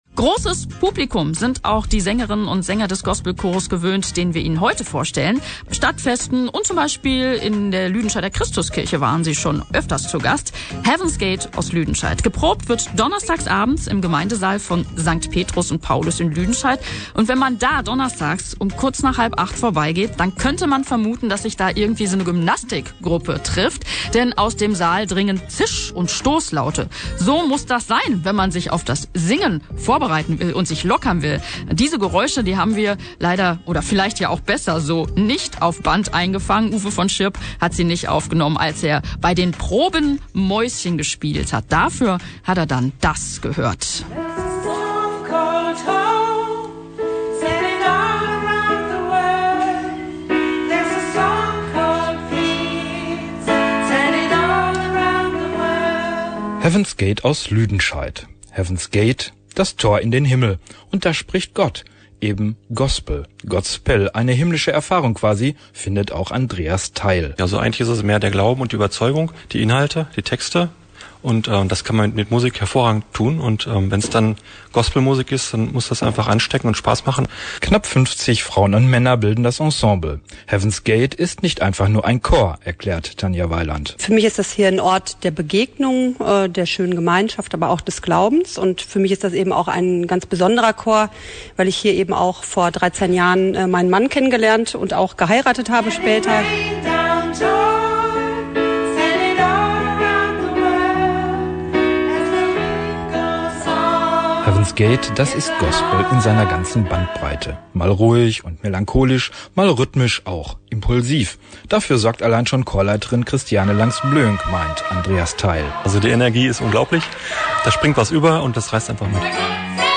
>> Ein tolles Porträt des Chores von Radio MK im Sommer 2015 <<
Founded in 1996, "Heavens Gate" Gospel Choir is an upbeat inspirational, musical ensemble with passionate singers.